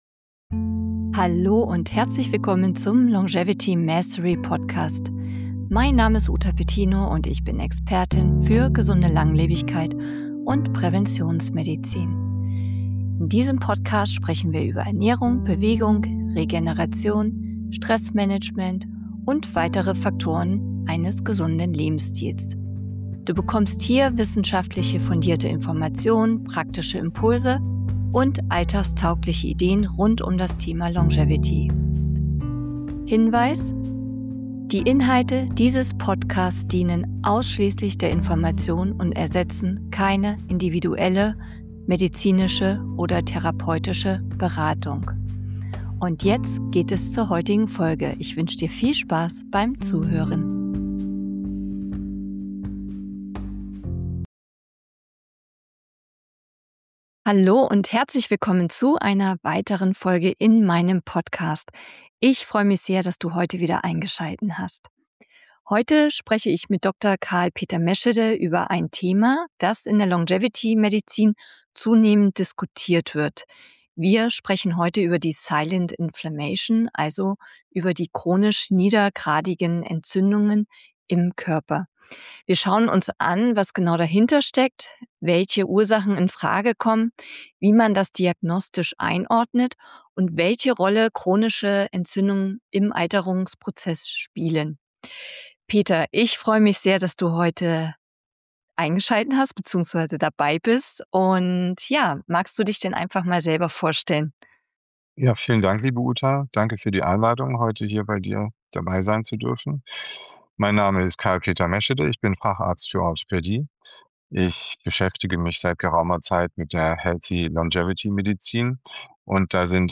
Stille Entzündungen – Ein Gespräch